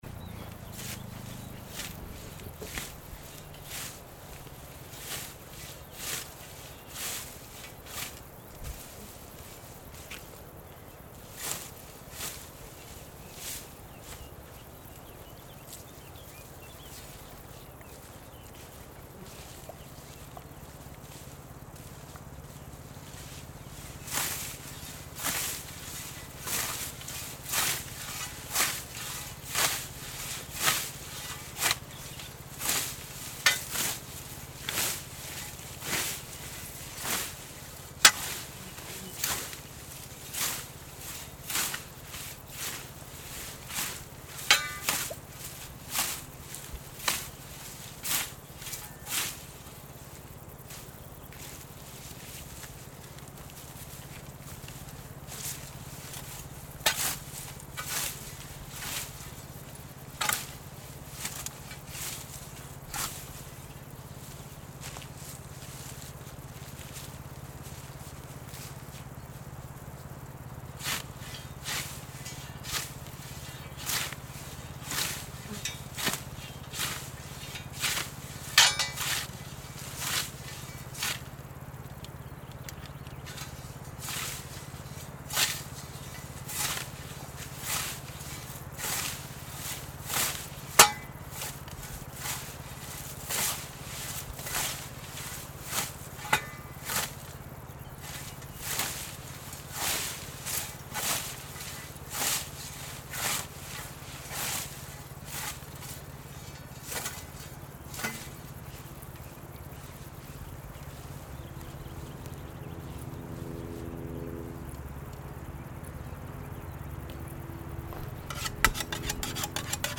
During my stay in Kippel in summer 2014 I took a series of walks around loetschental with different local inhabitants as well as on my own.
As outputs of my experience I got documentary materials of the walks, interviews, dialogues, paths and points that entailed unfolding narratives, images, etc., that were exhibited in Kunst im Stadl.